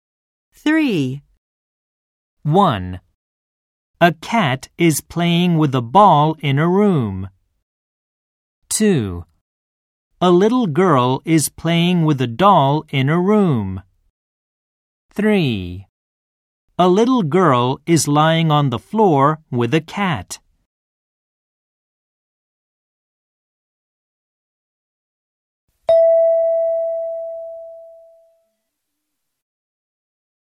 TEST 1 Listening B-(3) 0:31 241KB